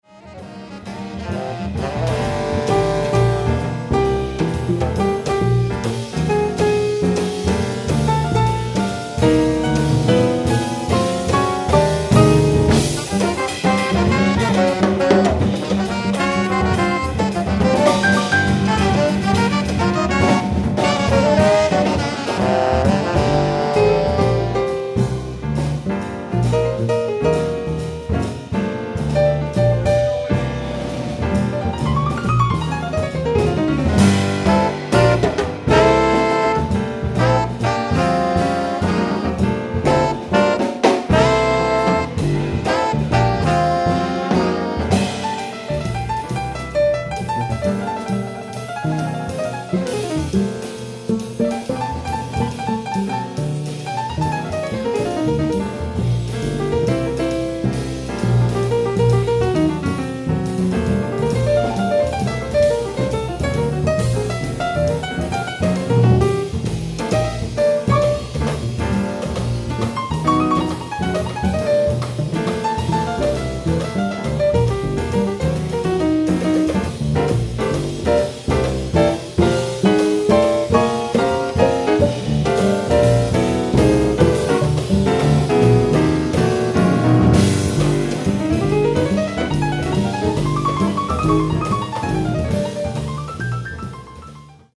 ライブ・アット・アウトドアー・フェステバル、ヴァンクーバー、ワシントン 10/22/1999
オフィシャル級のクオリティー！！
※試聴用に実際より音質を落としています。